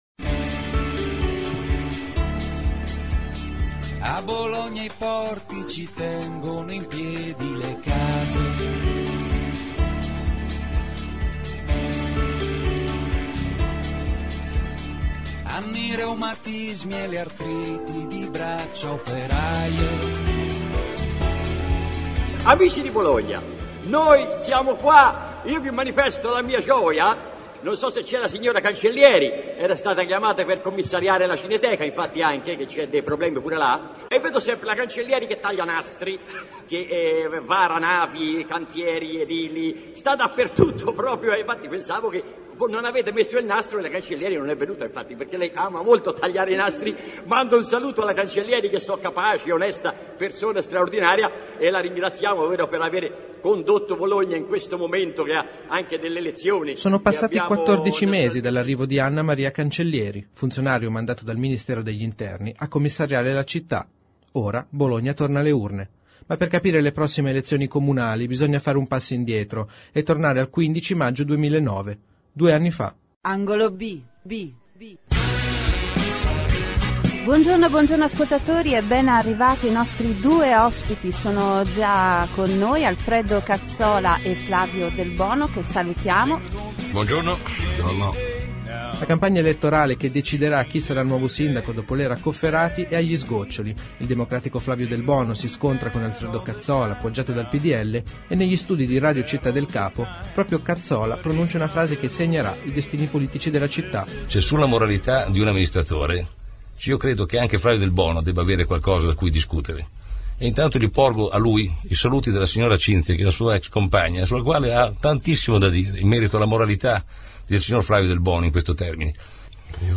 Siamo andati nei quartieri a sentire che aria tira.
Abbiamo tentato di delineare l’immagine di una città, Bologna, che dopo 14 mesi di commissariamento si avvia al voto. E lo abbiamo fatto sentendo cittadini, esperti, analisti politici e, ovviamente, dando spazio ai candidati che si sfideranno alle urne.